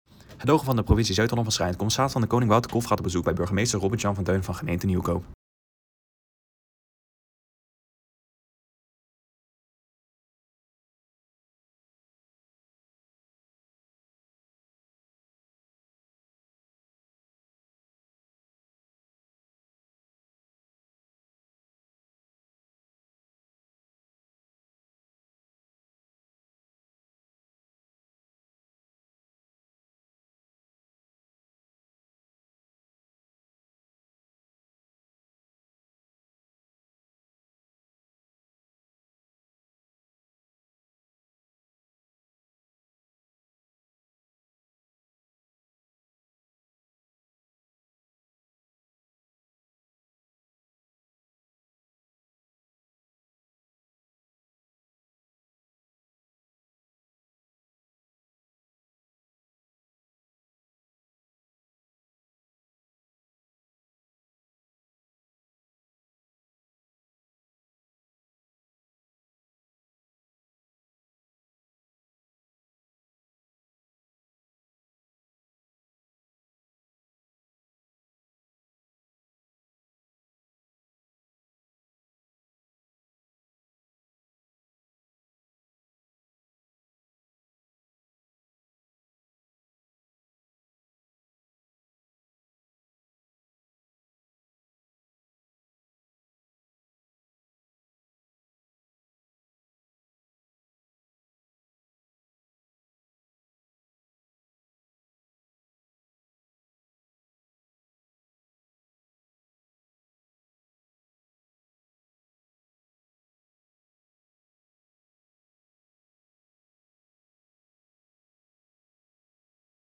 De commissaris van de Koning gaat in gesprek met de burgemeester van Nieuwkoop.